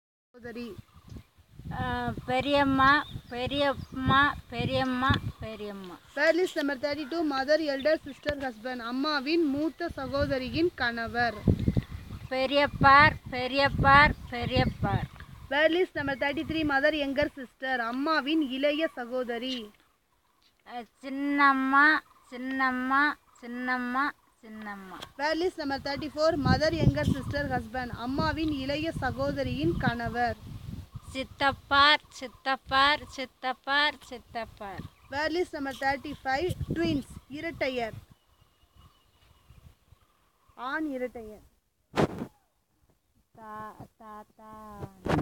NotesThis is an elicitation of words for kinship terms, using the SPPEL Language Documentation Handbook.